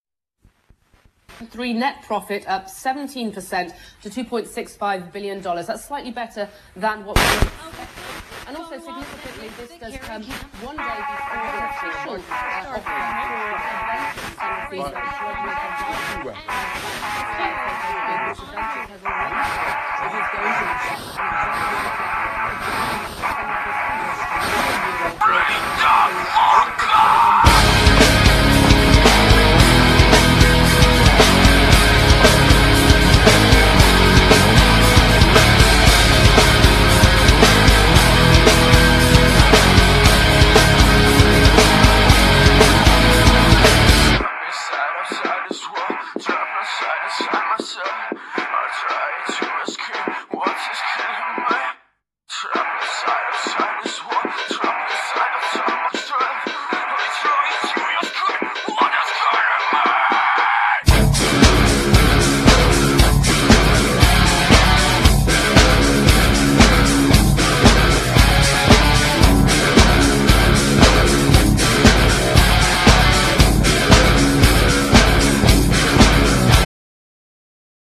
Genere : pop rock